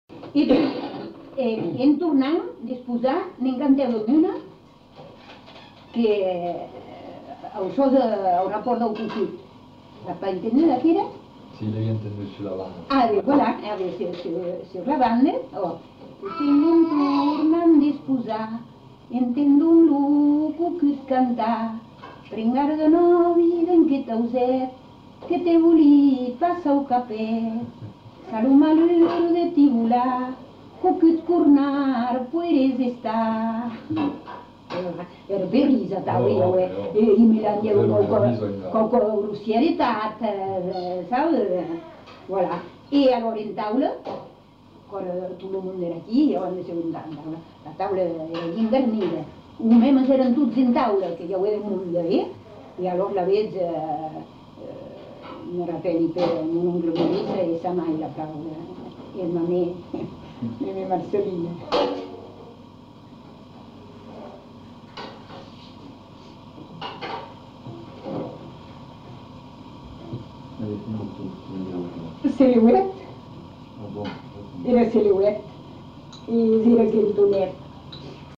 Aire culturelle : Marmandais gascon
Genre : chant
Effectif : 1
Type de voix : voix de femme
Production du son : chanté
Notes consultables : Chant suivi de commentaires.